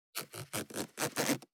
413,ジッパー,チャックの音,洋服関係音,ジー,バリバリ,カチャ,ガチャ,シュッ,
ジッパー効果音洋服関係